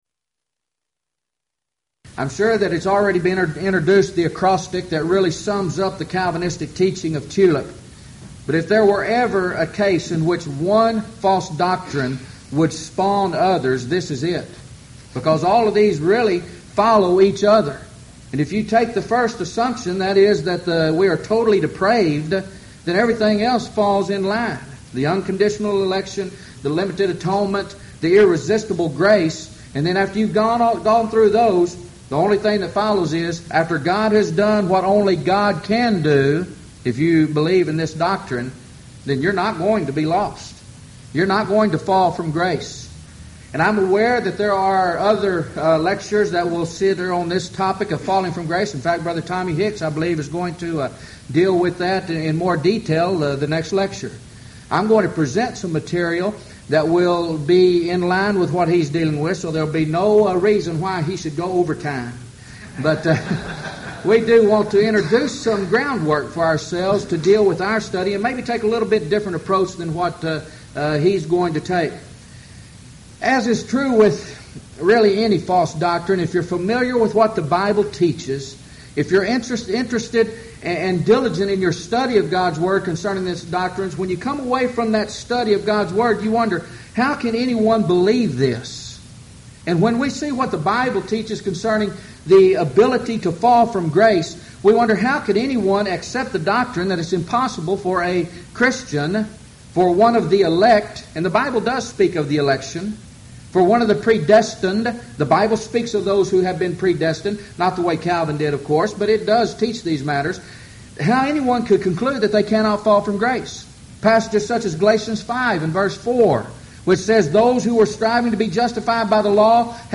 Event: 1998 Houston College of the Bible Lectures